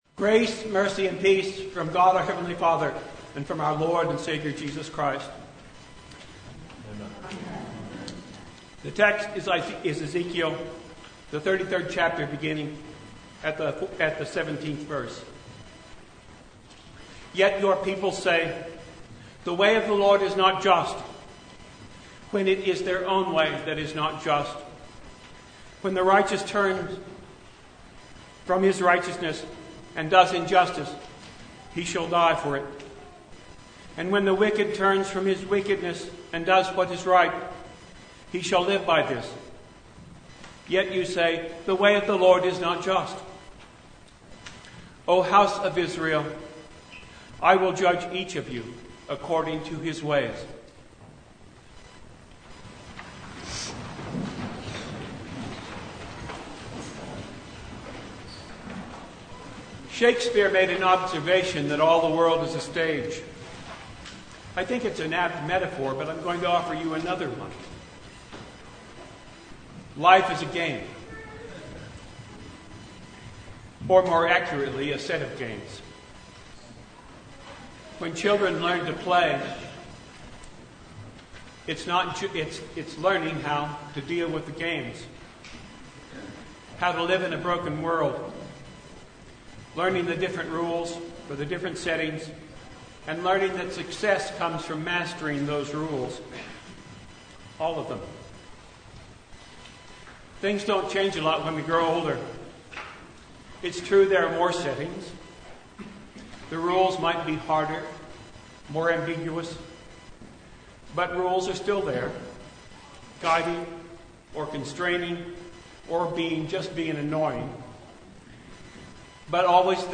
March 20, 2022 Suffering, Evil, and God in Christ Passage: Ezekiel 33:17-20; Luke 13 1-9 Service Type: Sunday Suffering and evil are real problems, so God took real action.